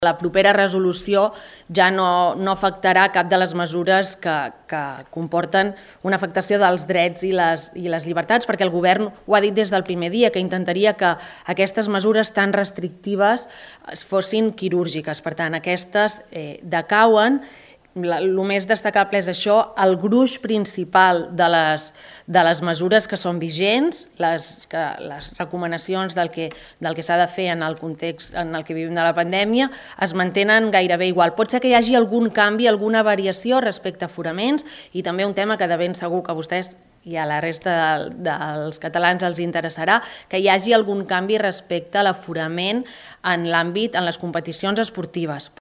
Ho ha anunciat la portaveu del govern, Patrícia Plaja, en la roda de premsa posterior al Consell Executiu.